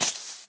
minecraft / sounds / mob / creeper / say2.ogg